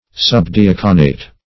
Search Result for " subdiaconate" : The Collaborative International Dictionary of English v.0.48: Subdiaconate \Sub`di*ac"o*nate\, a. Of or pertaining to a subdeacon, or to the office or rank of a subdeacon.
subdiaconate.mp3